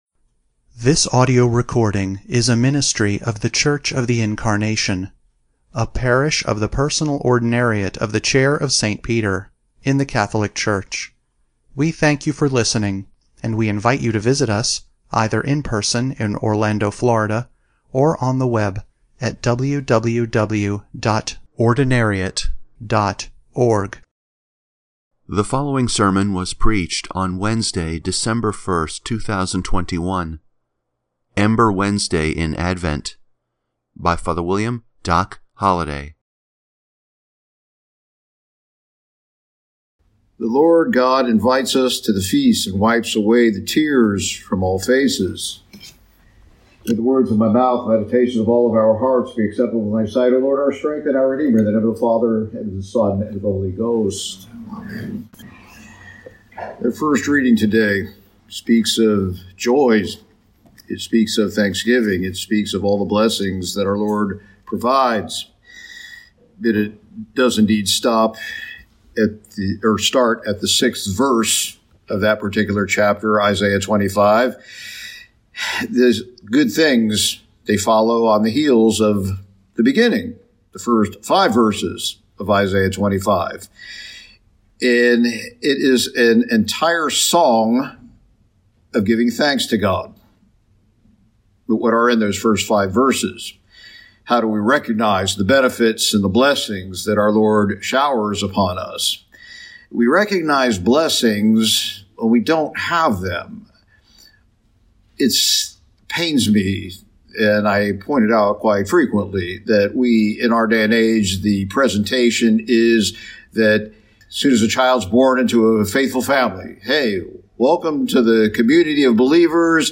Sermon 12/1/2021 – Ember Wednesday in Advent